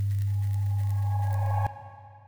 Humm_Rev.wav